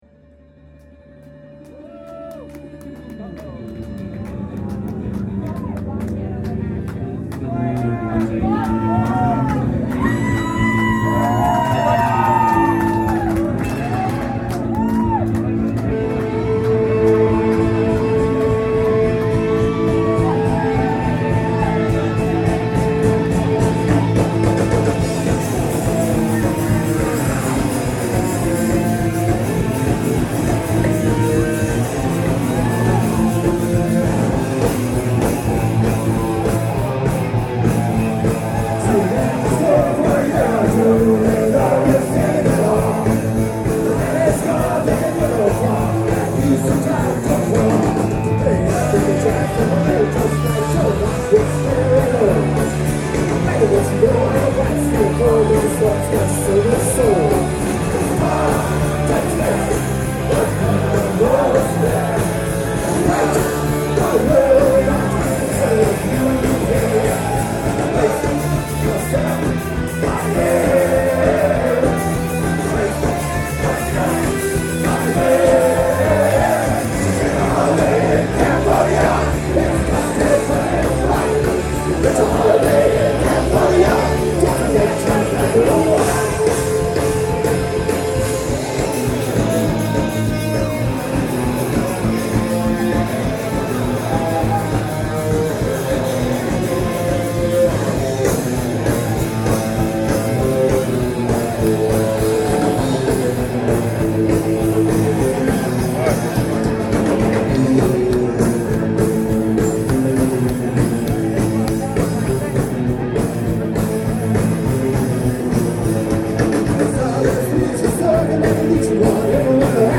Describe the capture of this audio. Rocks Off Cruise – May 28, 2010